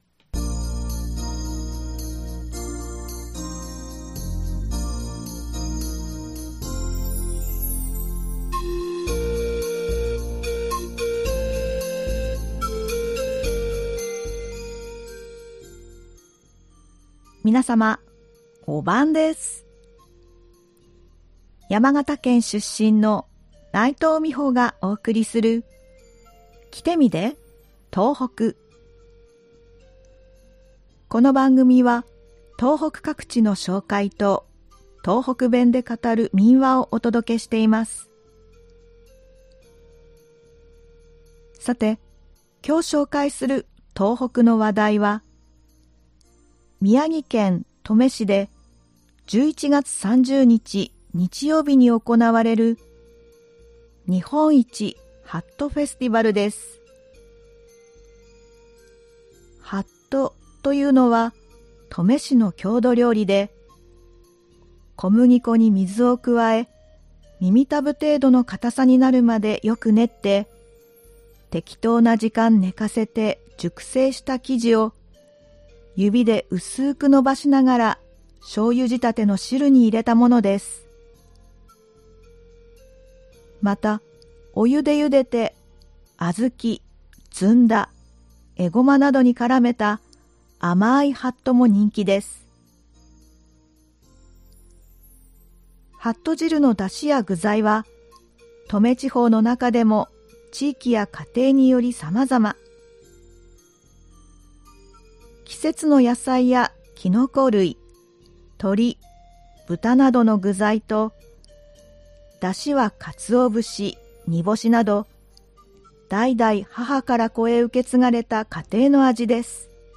この番組は東北各地の紹介と、東北弁で語る民話をお届けしています（再生ボタン▶を押すと番組が始まります）